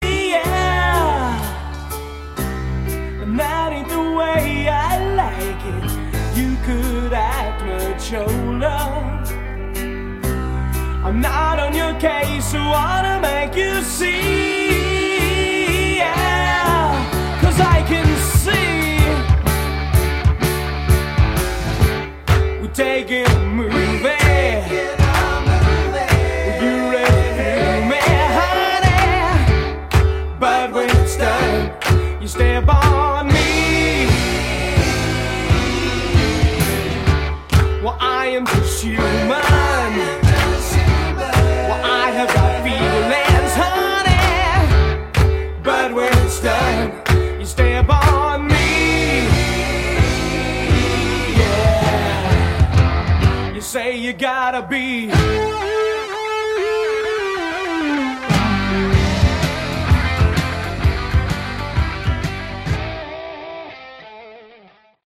Category: Pomp AOR